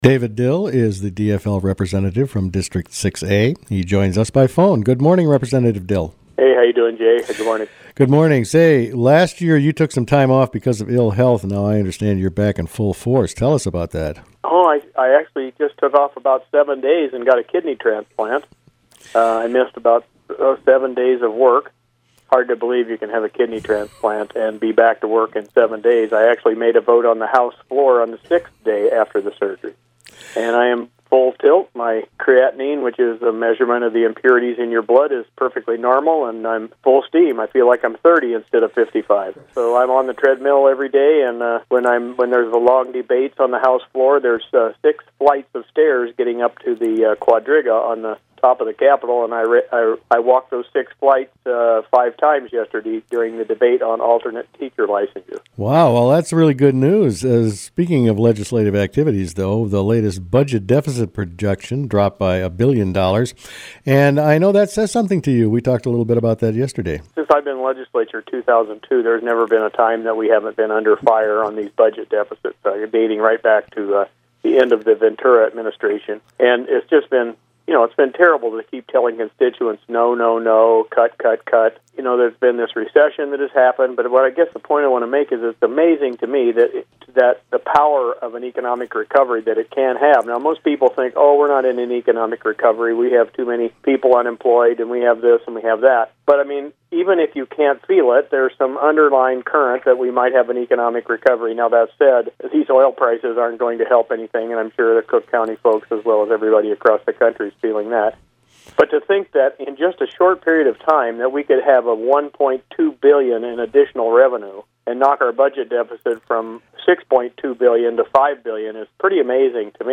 Rep. David Dill interviewed on the current legislative session